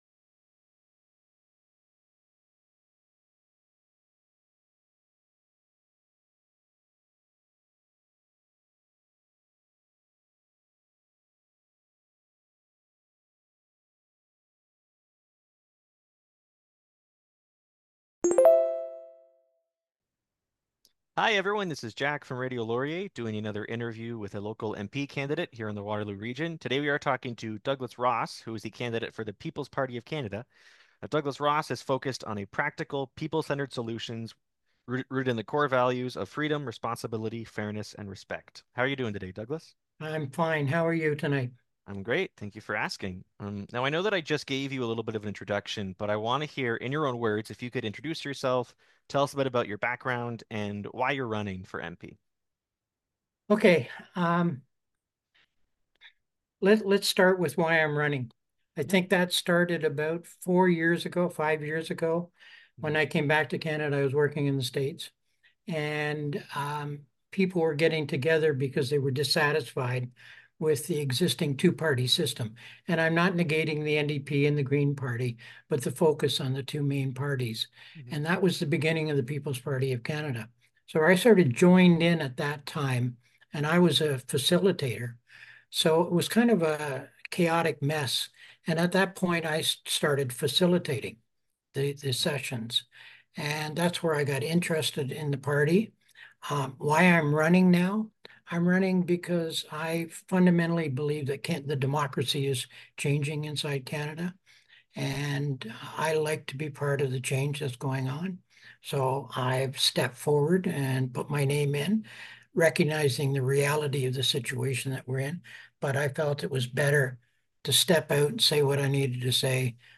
Interviews – Radio Laurier